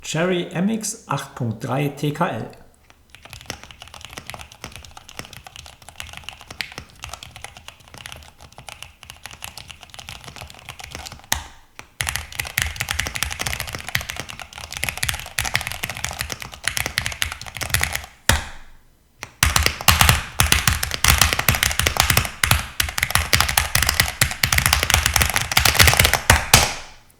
Dafür hält sie sich akustisch zurück.
Akustik: Eher klassisch
Im Bereich Akustik fällt die MX 8.3 positiv auf, weil sie nicht auffällt und sich dem Trend zu präsentem „Tock“-Sound entzieht. Allerdings gehen ihr auch die präzisen Anschläge ab, die High-End-Tastaturen aufbieten.
Alle klingen stärker „Premium“, der MX 8.3 haftet ein Rest „Kunststoff-Klackern“ an.
• Dezenter Klang